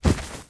stray_dog
drop_1.wav